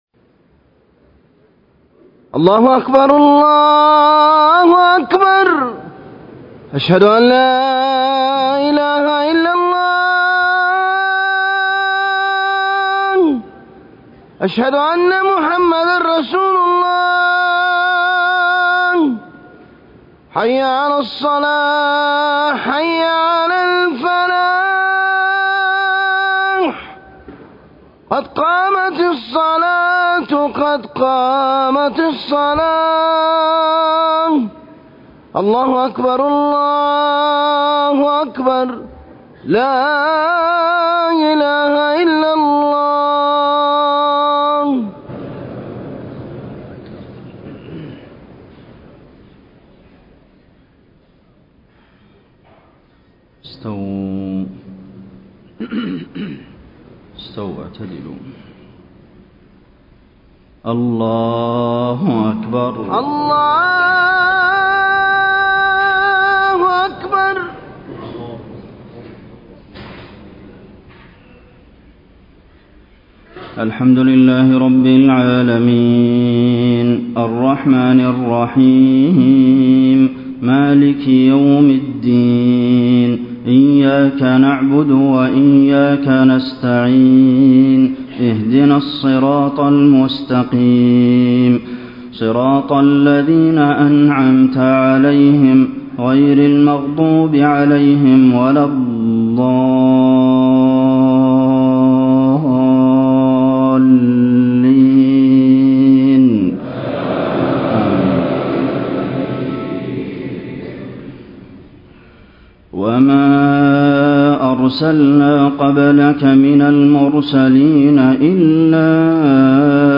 صلاة الجمعة 3-5-1434 من سورة الفرقان > 1434 🕌 > الفروض - تلاوات الحرمين